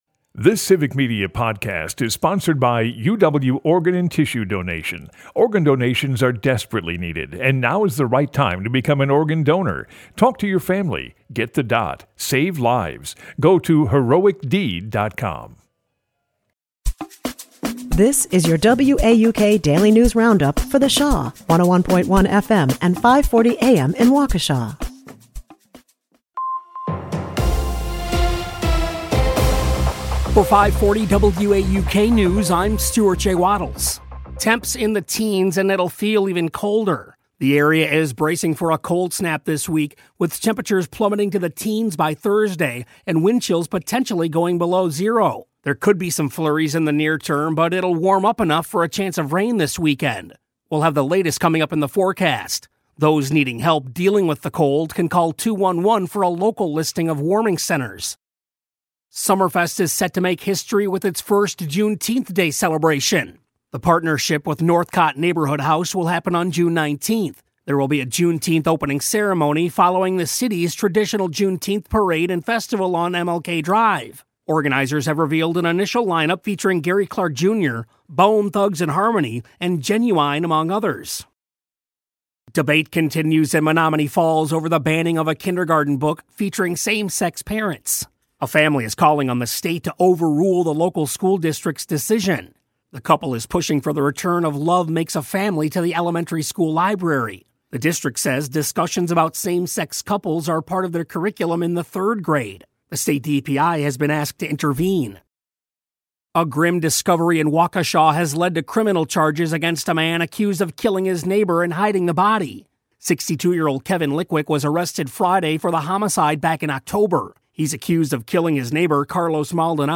WAUK Tuesday News Roundup